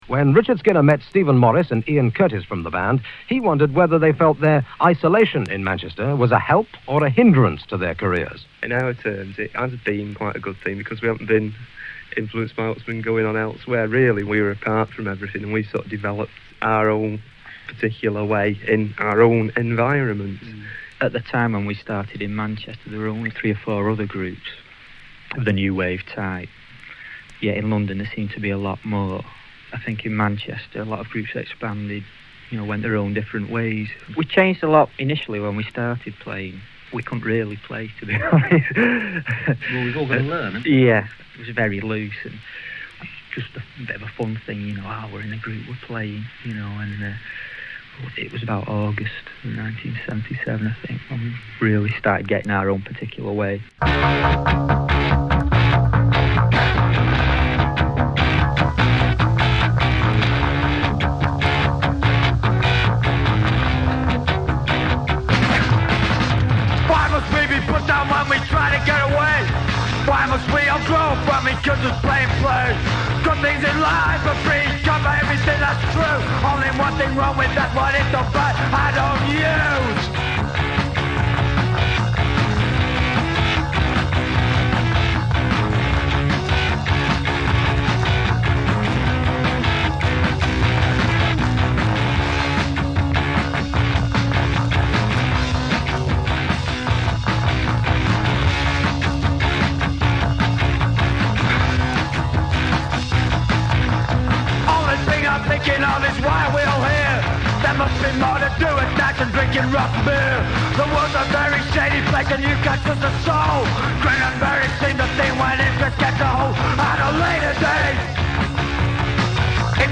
Funk/Soul Reggae Rock